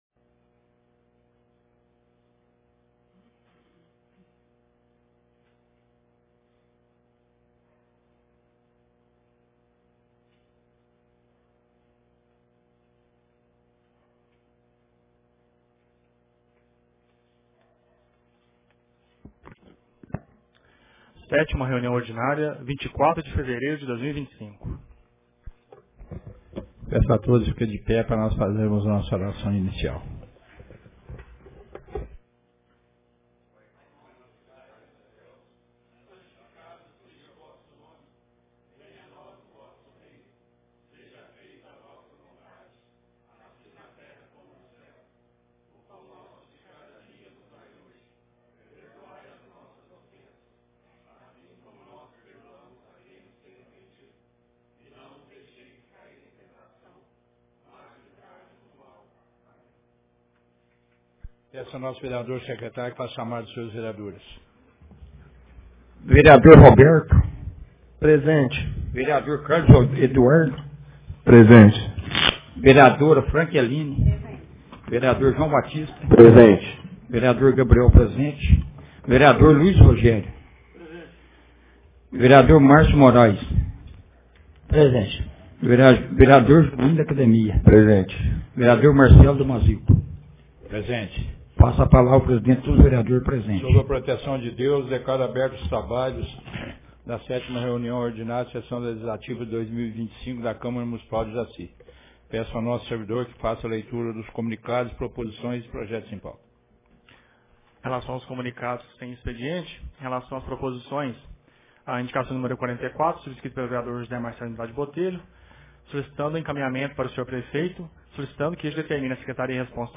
Ata da 7ª Reunião Ordinária de 2025